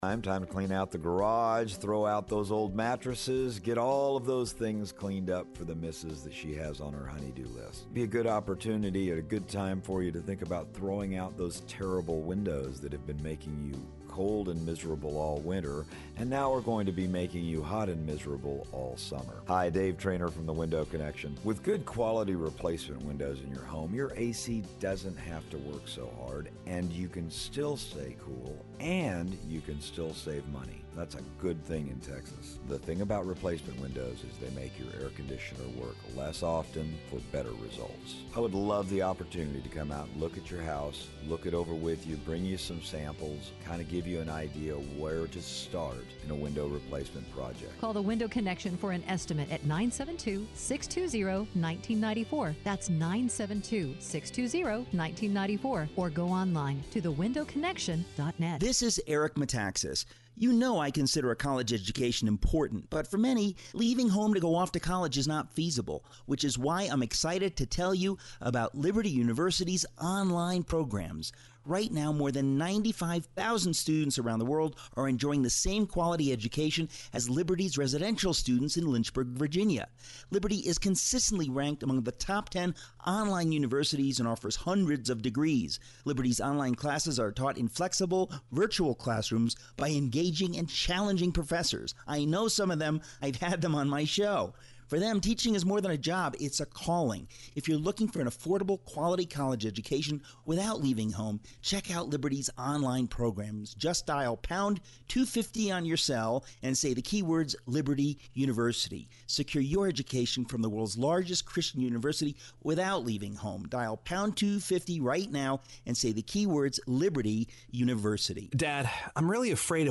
Interview with Texas AG Ken Paxton on Executive Authority under the Constitution
Texas Attorney General Ken Paxton called in to talk about his argument before the US Supreme Court on the authority of the President to unilaterally change law.